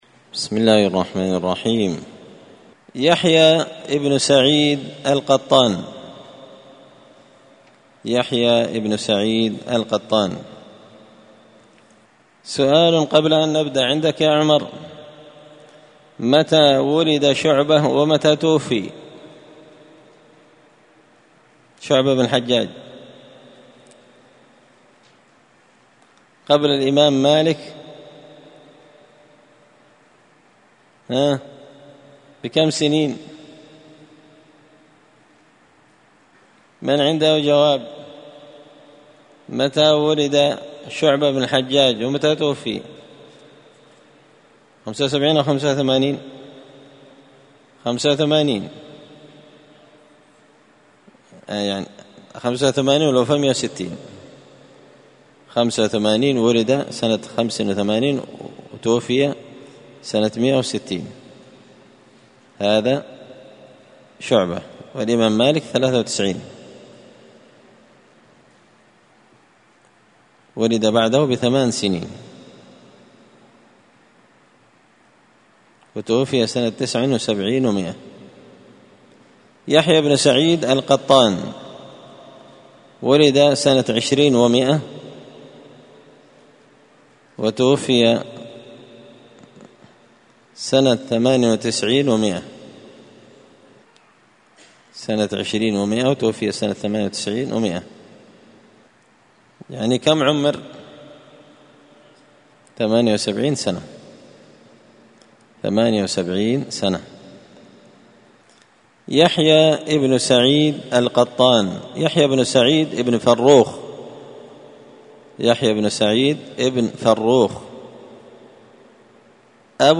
مسجد الفرقان قشن_المهرة_اليمن
السبت 20 ذو الحجة 1444 هــــ | الدروس | شارك بتعليقك | 23 المشاهدات